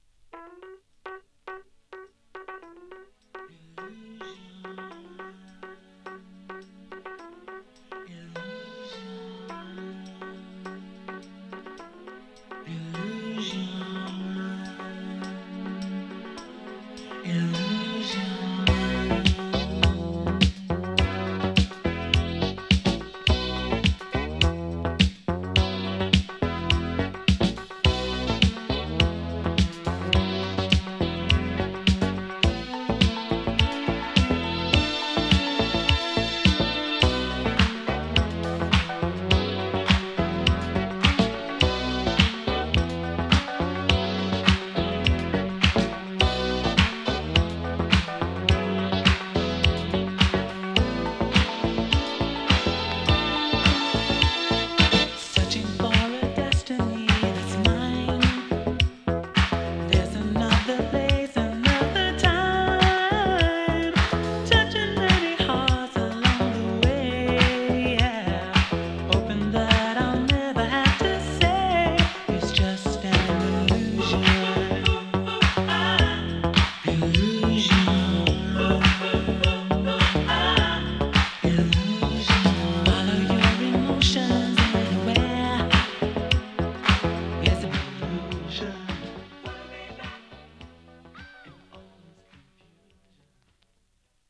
ディスコ
盤 ジャケ M- VG 1982 UK R&B 12
♪Vocal (6.20)♪